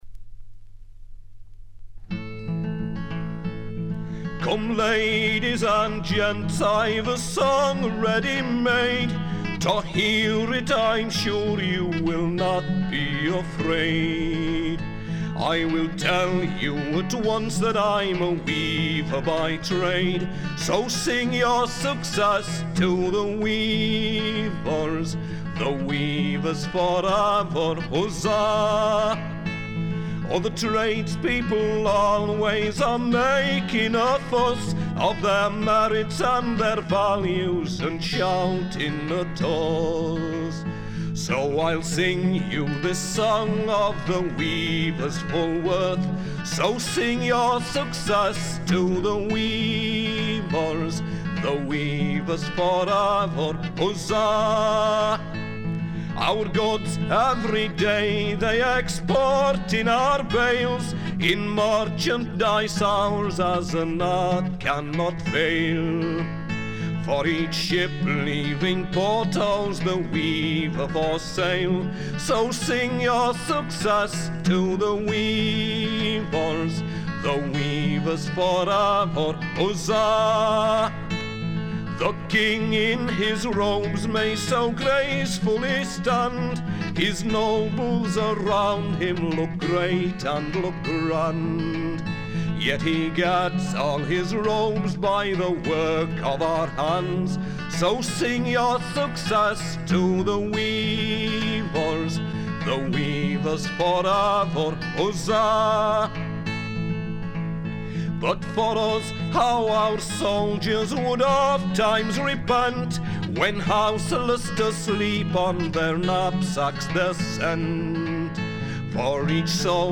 folk singer
his fine voice and lively interpretation of traditional English songs seemed to me to put him in the first rank of British singers.